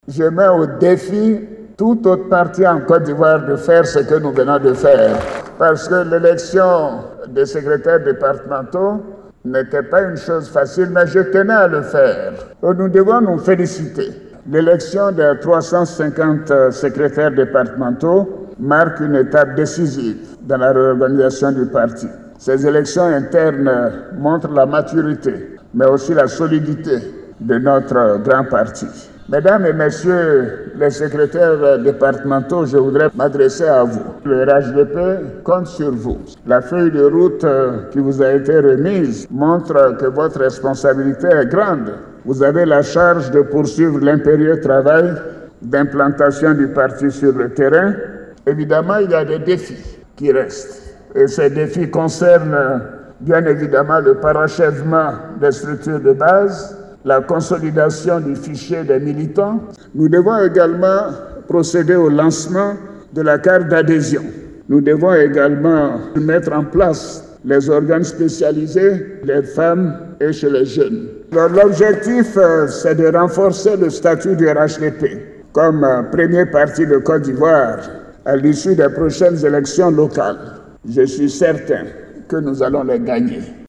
Au cours d’une réunion du Conseil Politique, le Président de ce parti, Alassane OUATTARA, a mis en mission les 350 animateurs animateurs de ce parti à la base, au cours de son discours d’orientation.